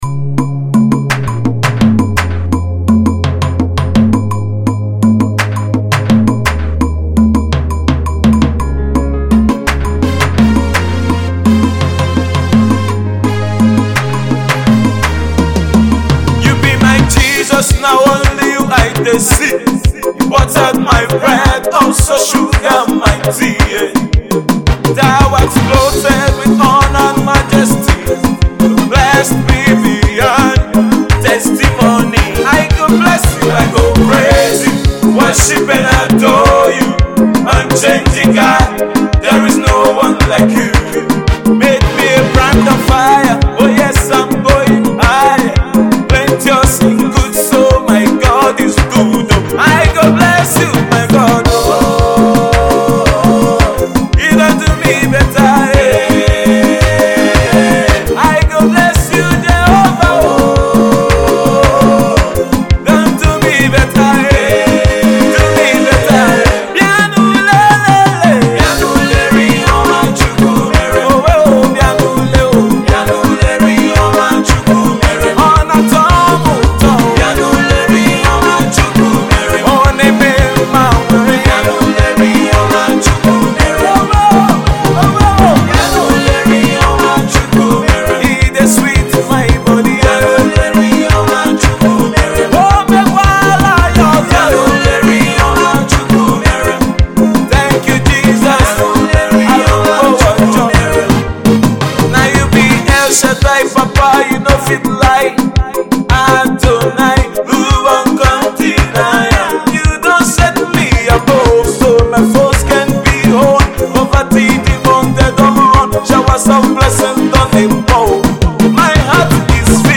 gyration praise song